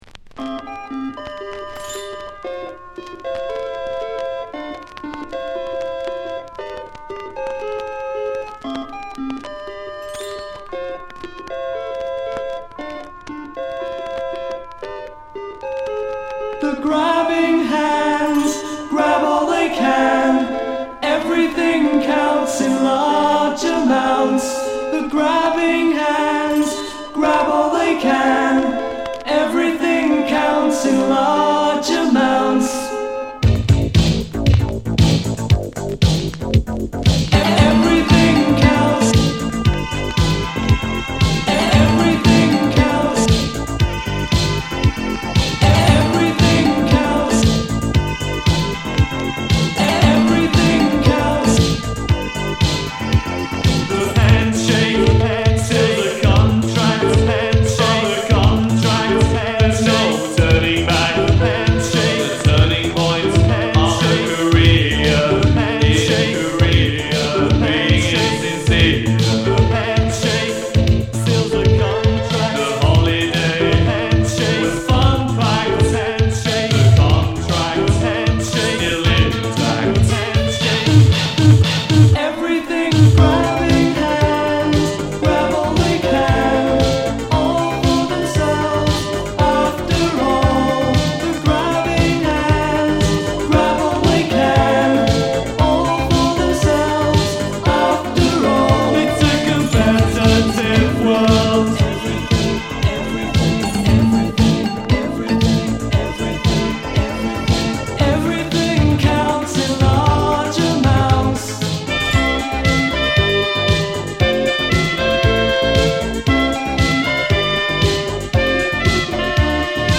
バシバシに強化されたビートも迫力があります。